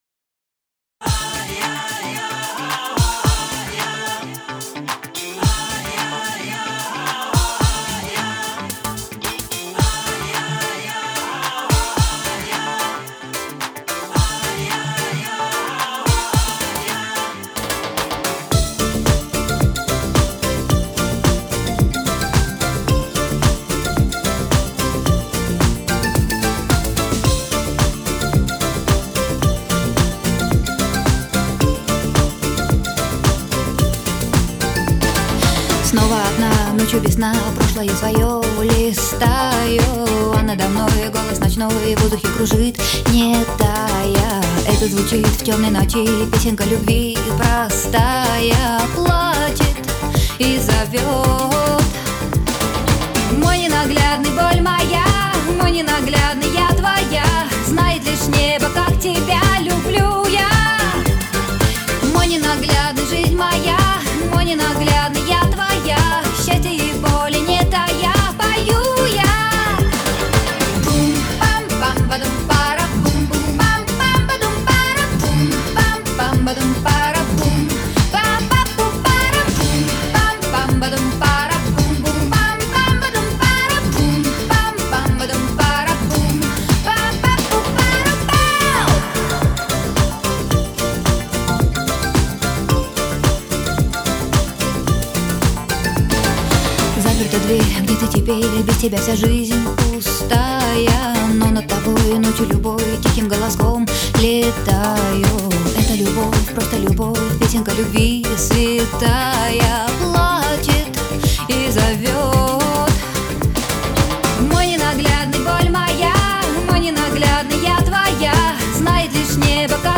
Хиты 80-х, 90-хРусскаяРусский ПопРусская эстрада